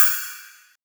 • Long Ride Sound Sample E Key 06.wav
Royality free ride cymbal tuned to the E note. Loudest frequency: 12450Hz
long-ride-sound-sample-e-key-06-dqj.wav